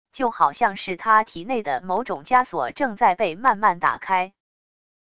就好像是他体内的某种枷锁正在被慢慢打开wav音频生成系统WAV Audio Player